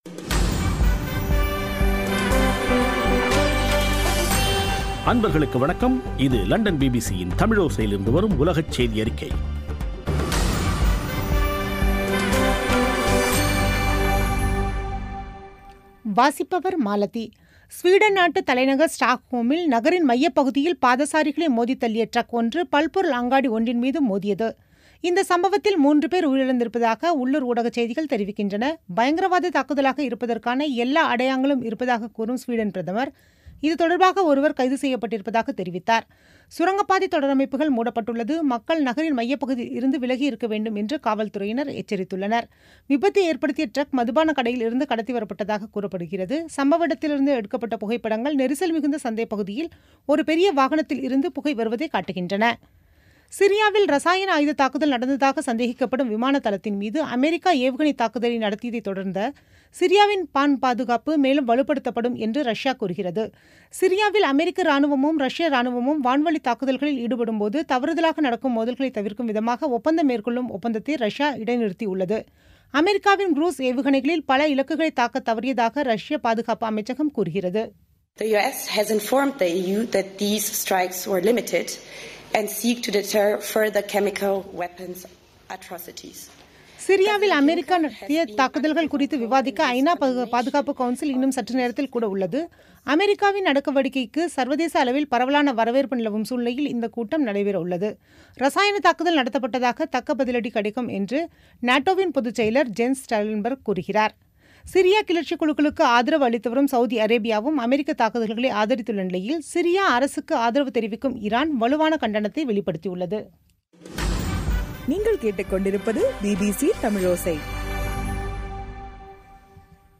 பிபிசி தமிழோசை செய்தியறிக்கை (07/04/2017)